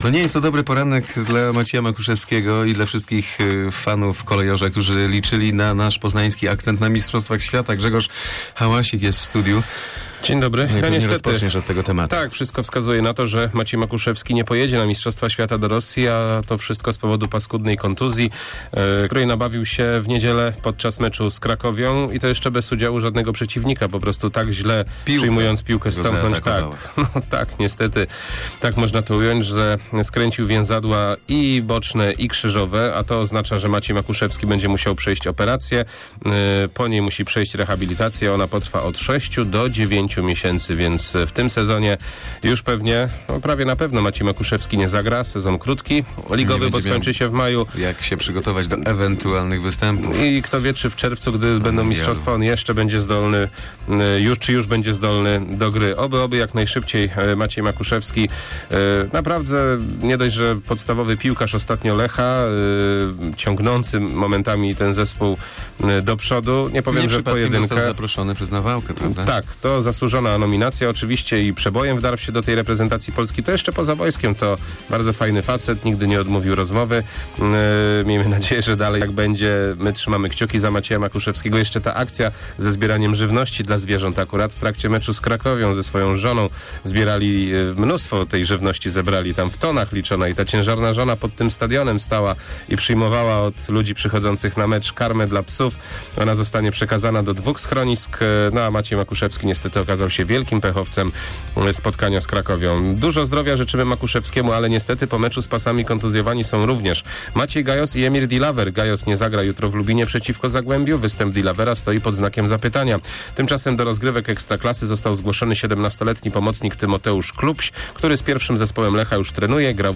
12.12 serwis sportowy godz. 7:45